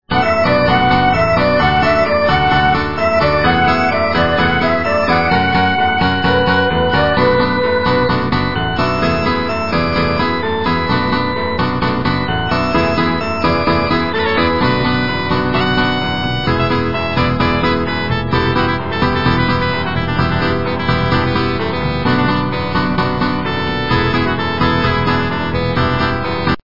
русская эстрада
полифоническую мелодию